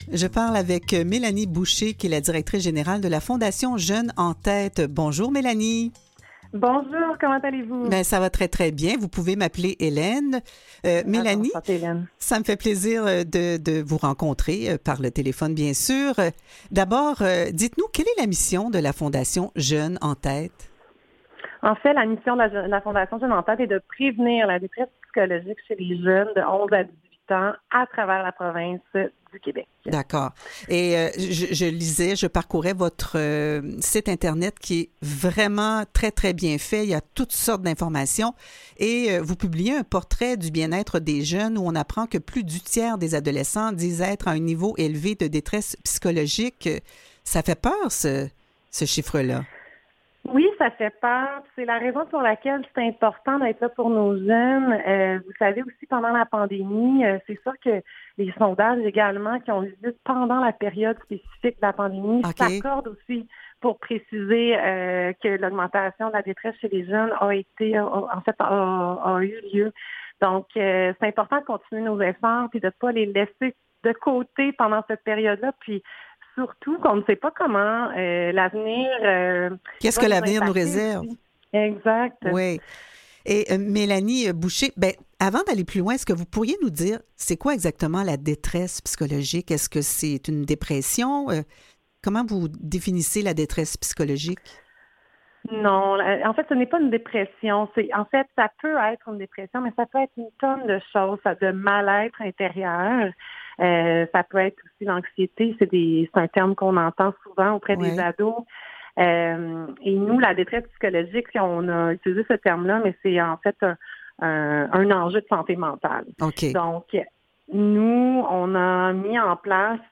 Aux Quotidiens Revue de presse et entrevues du 25 septembre